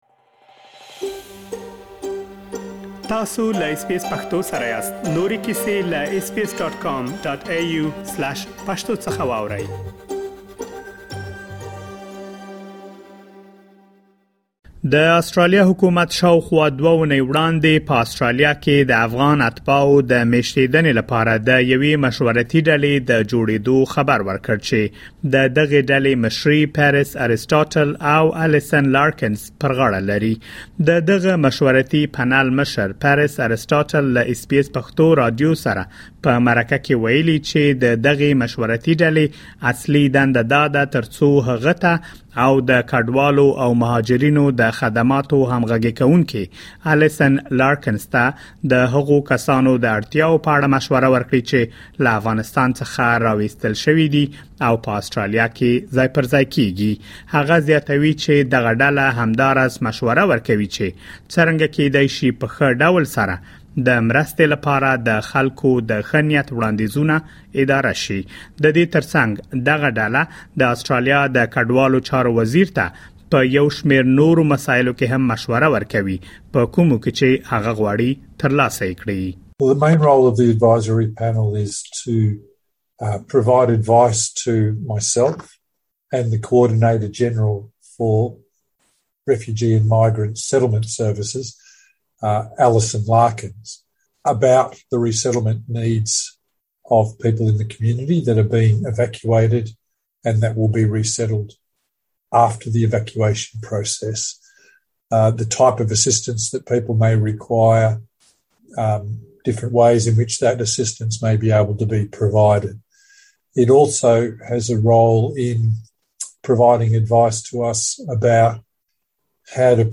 مرکه ترسره کړې ترڅو تاسو د مشورتي ډلې له دندې او مسئولیتونو خبر شئ.